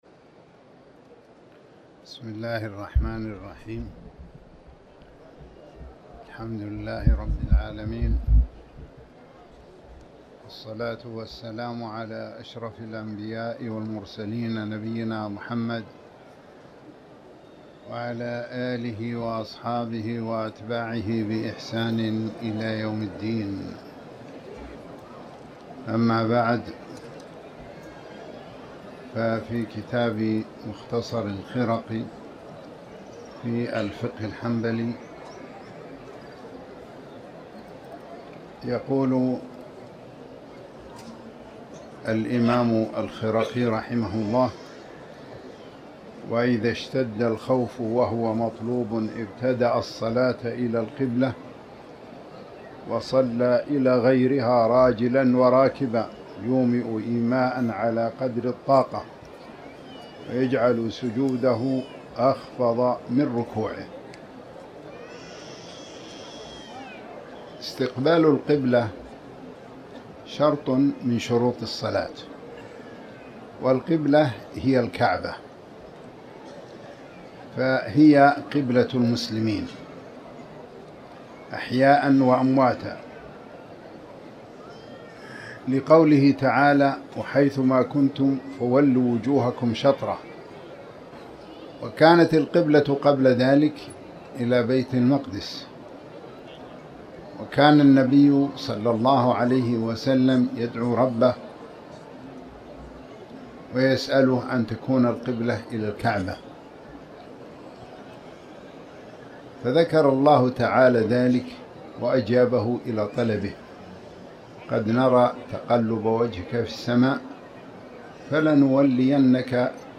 تاريخ النشر ٢٤ جمادى الأولى ١٤٤٠ هـ المكان: المسجد الحرام الشيخ